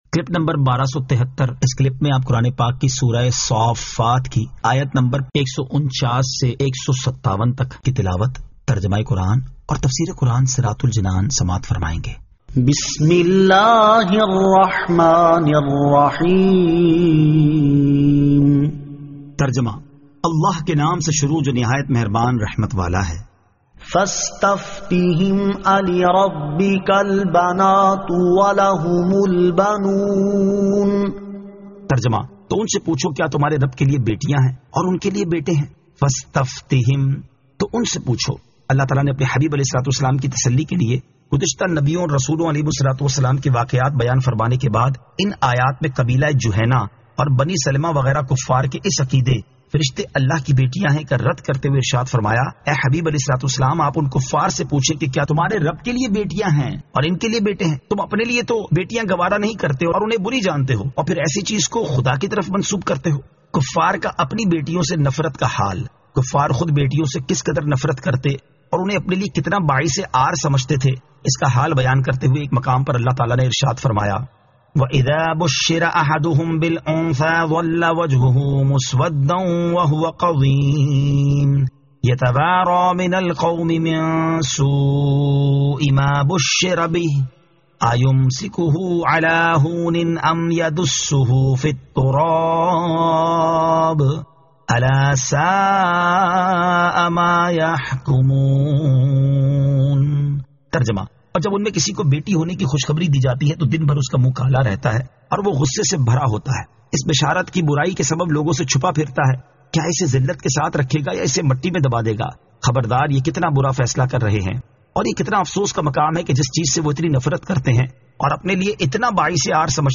Surah As-Saaffat 149 To 157 Tilawat , Tarjama , Tafseer